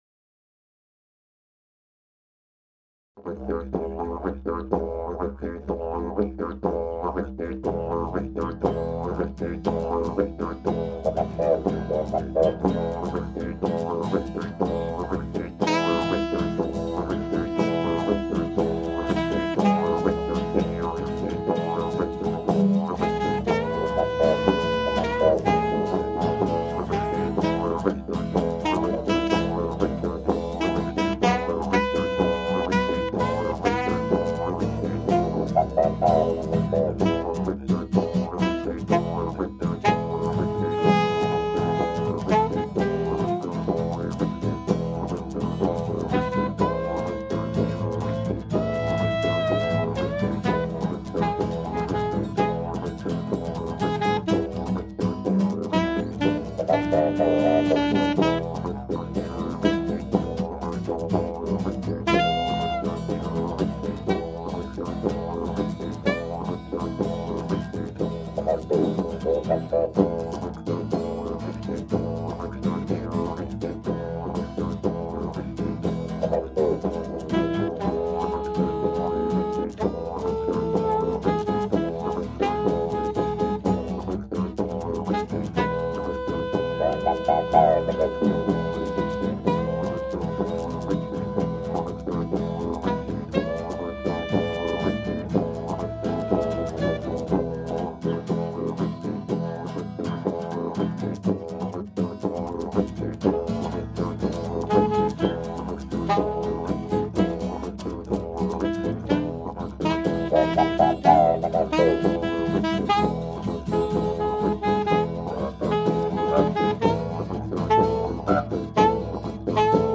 chant de gorge, gratt, et djembé
Saxo
Didg, guimbarde et bérimbao